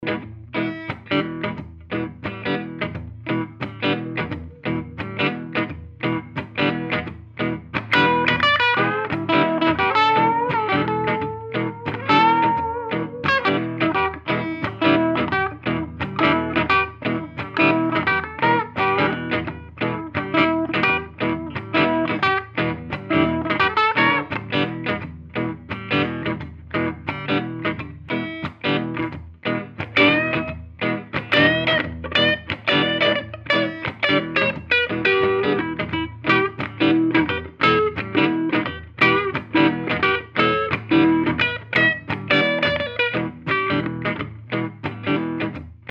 Sinon il me semble que je vous avais pas fait ecouter le duo G5 Eminence Canabis Rex donc voila un petit sample fait rapidement.
Ca sonne très bien !